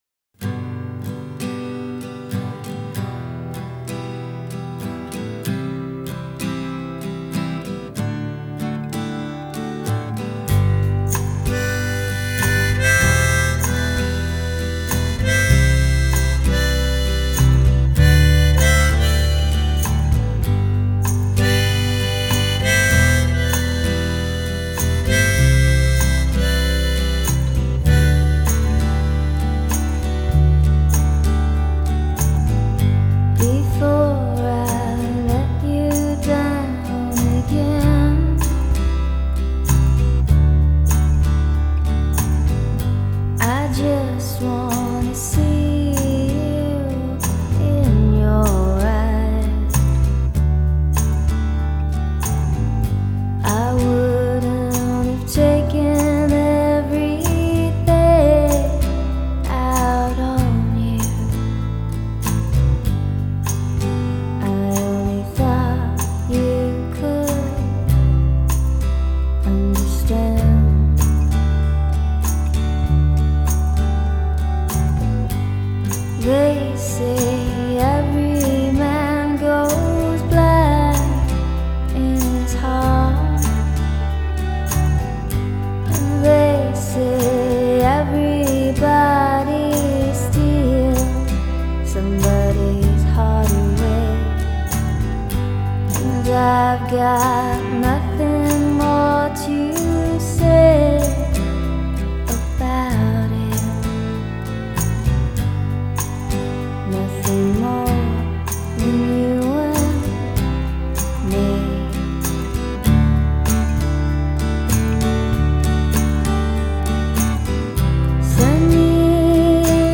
Alternative/Indie Country Rock